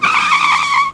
Skid_01.wav